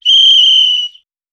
Whistle Intense Blow.wav